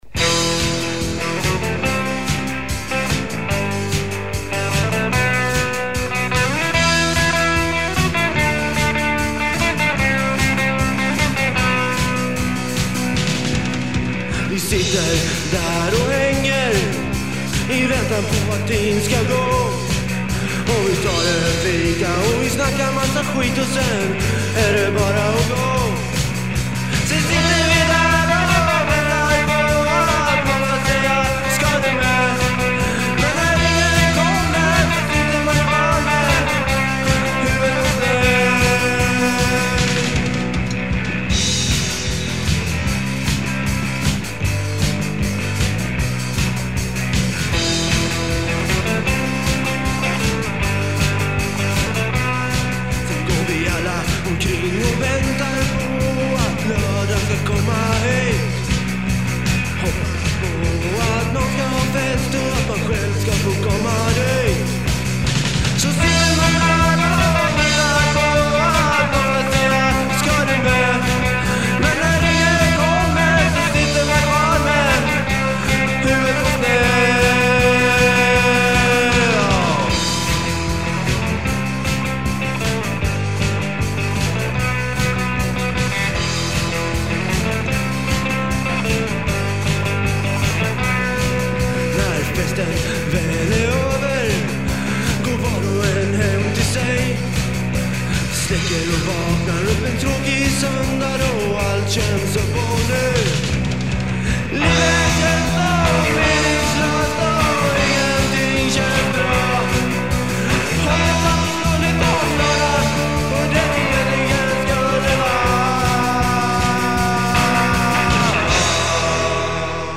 Guitar
Drums
Bass
Voice